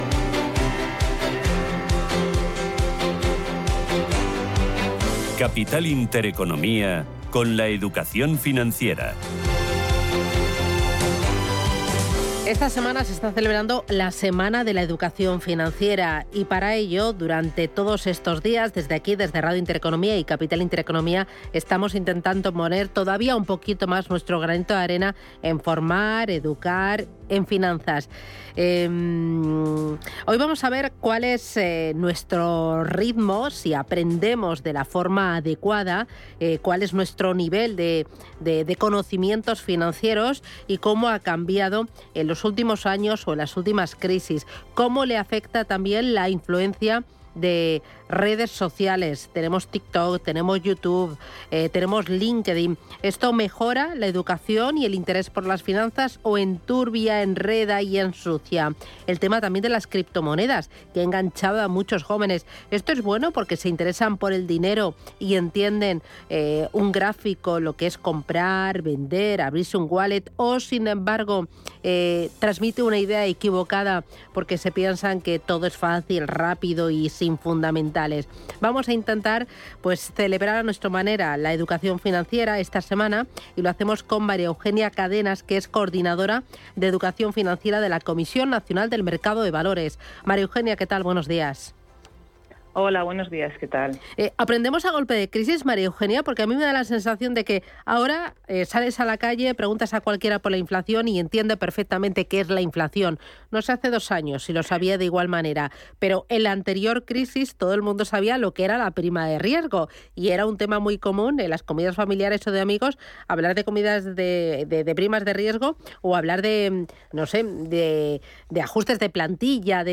Entrevista_CNMV_Educacion_Financiera_0410.mp3